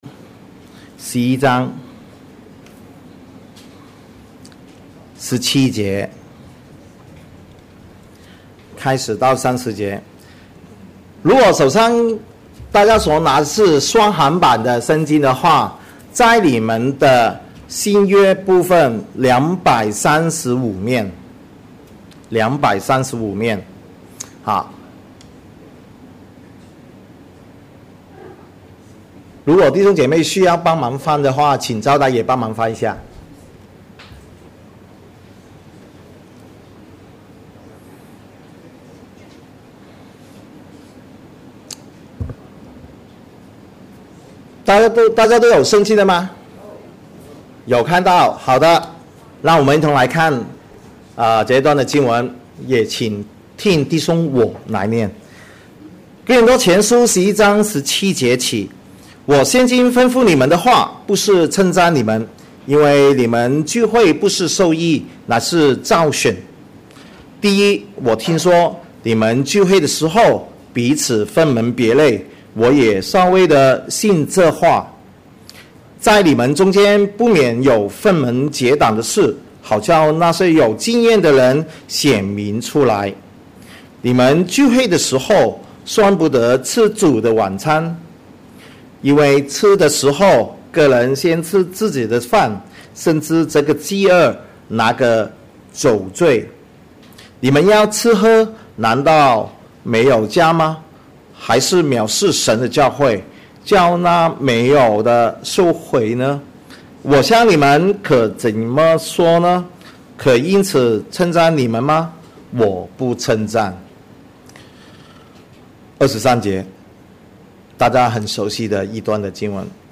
Posted in 主日崇拜